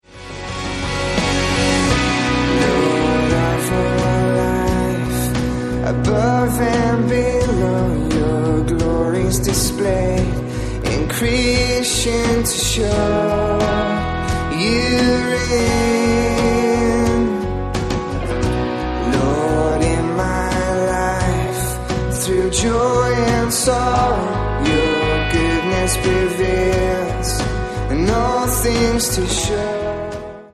• Sachgebiet: Praise & Worship